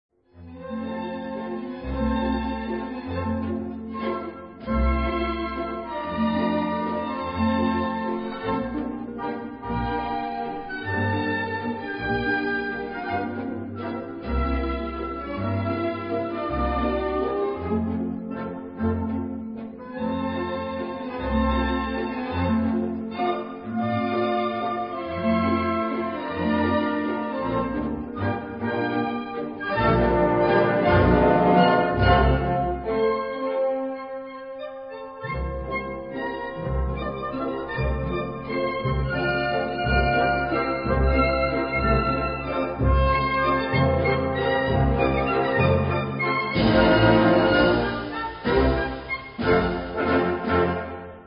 ein Melodiestück